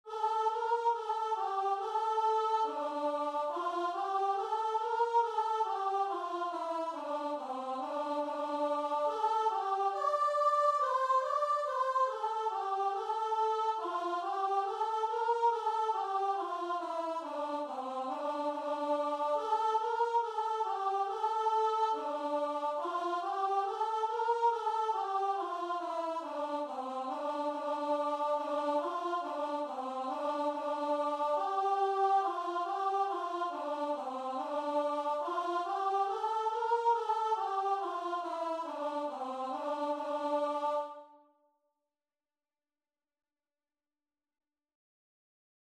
Free Sheet music for Choir
4/4 (View more 4/4 Music)
F major (Sounding Pitch) (View more F major Music for Choir )
Christian (View more Christian Choir Music)